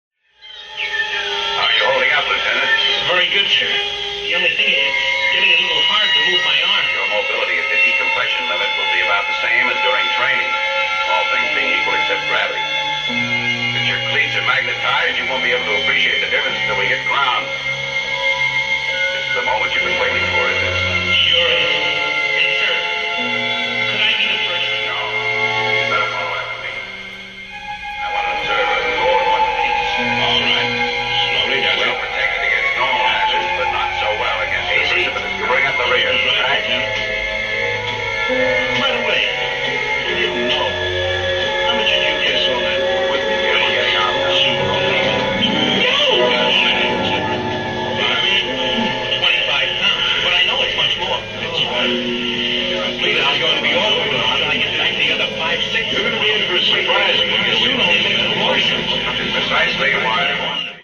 声は使わず、オルゴール、ターンテーブル、そして7つの発振器を内蔵するシドラッシ・オルガンを用いている。
まるでメビウスの輪の表面をなぞるようにドリームとナイトメアが次々に入れ替わって行く。